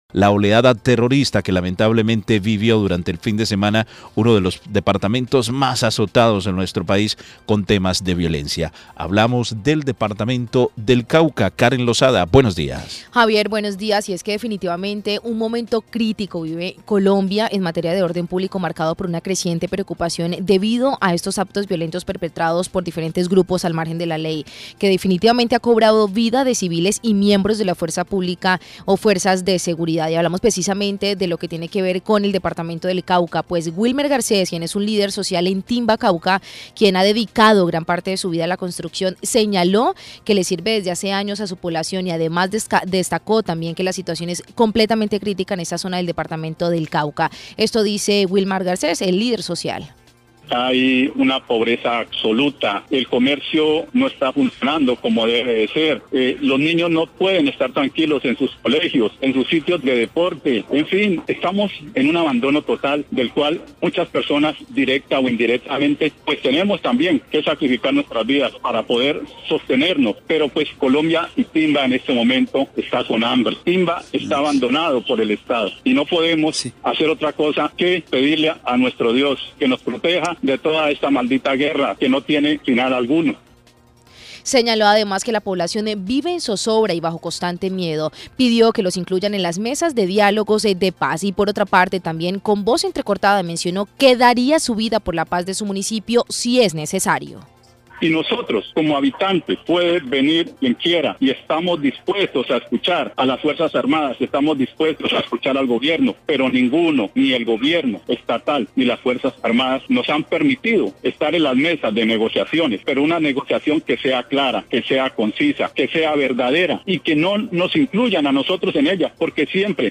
Señaló que la población vive en zozobra y bajo el constante miedo, que los incluyan en las mesas de diálogos, también con voz entrecortada mencionó que daría su vida por la paz de su municipio si es necesario.